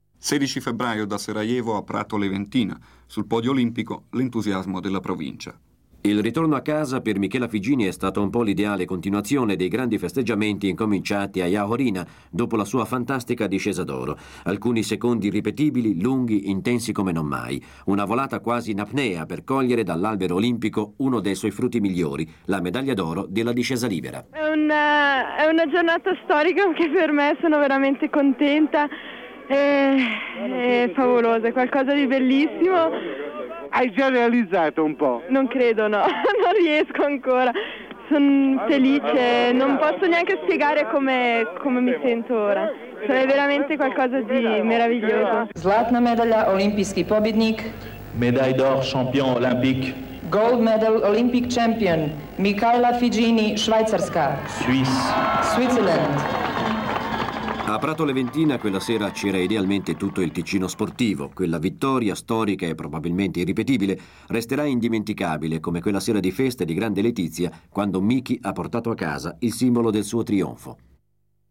A tre giorni dal suo storico trionfo nella discesa libera delle Olimpiadi di Sarajevo, Michela Figini riceve l'abbraccio di 3'000 tra amici e tifosi radunatisi a Rodi. Per la sciatrice leventinese l'emozione è ancora grandissima. 19.02.1984.